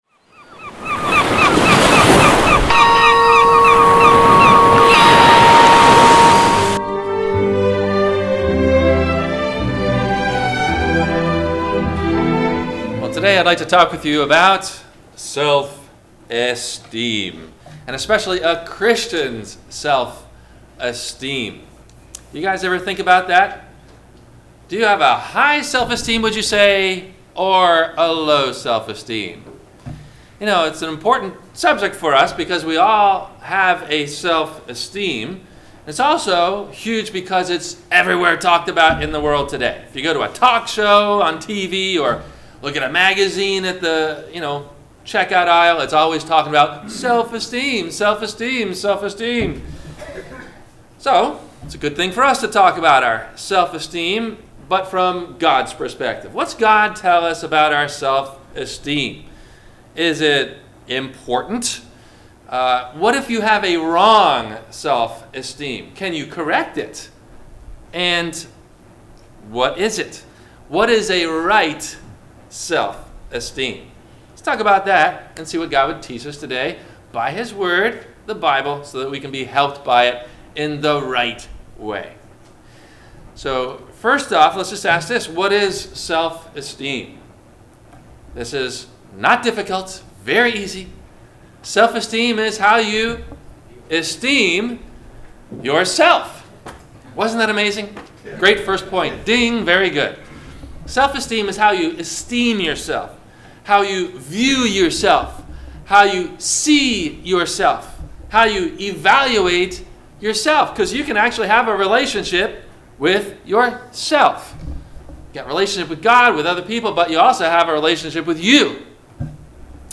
How’s Your Christian Self Esteem? – WMIE Radio Sermon – July 29 2019